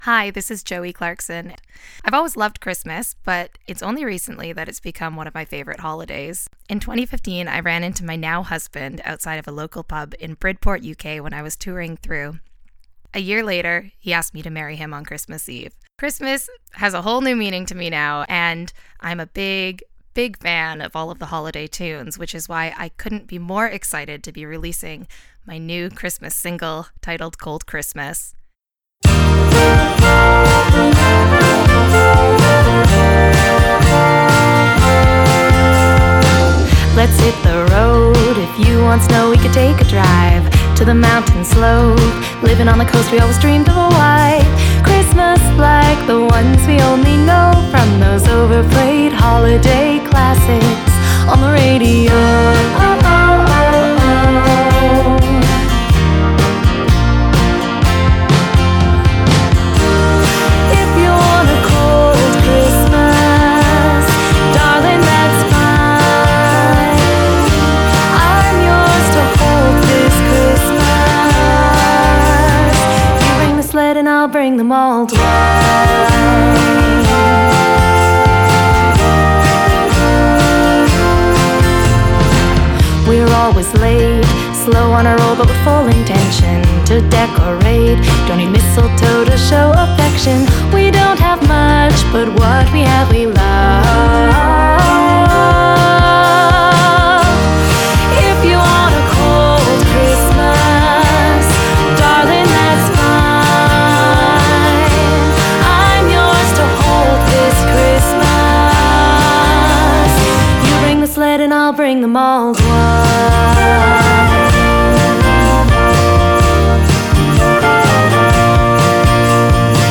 Original & traditional Christmas songs by indie artists, plus fave memories they share